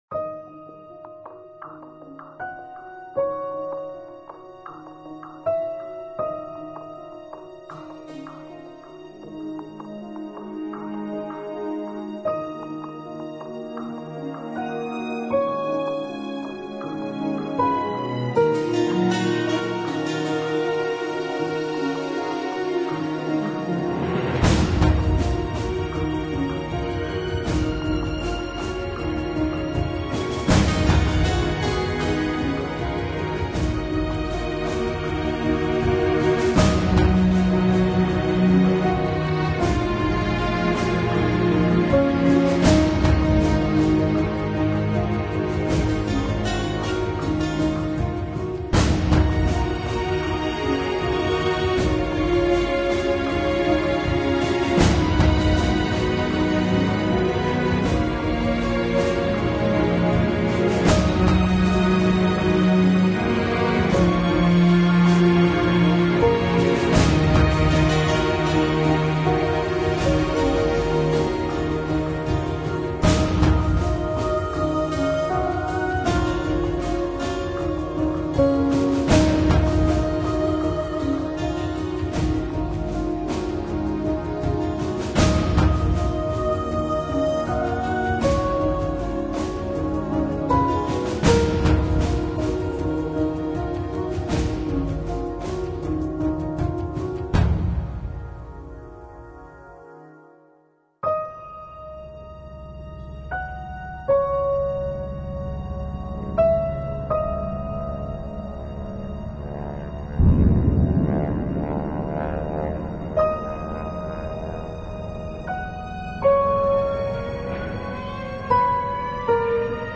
描述：带有强烈钢琴动机的戏剧性音乐作品，混合了管乐团和合唱团的背景。
Sample Rate 采样率16-Bit Stereo 16位立体声, 44.1 kHz